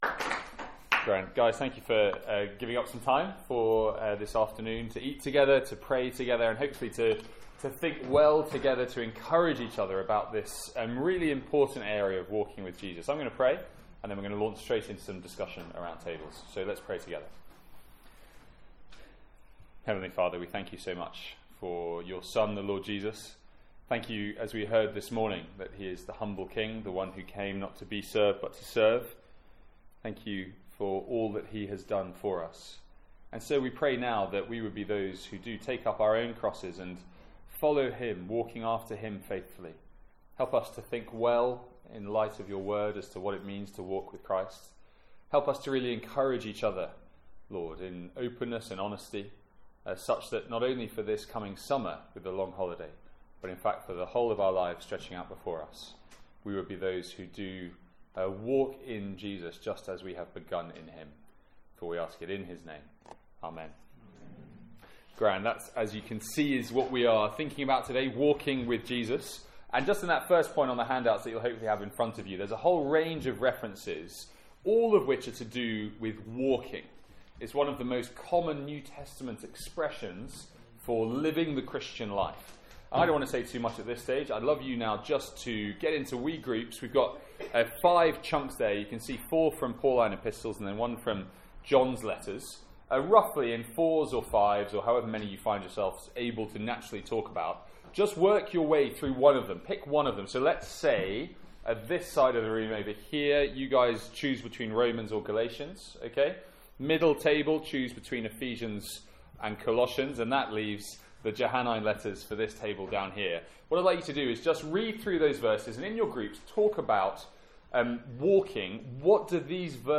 From our student lunch on 30th April, 2017.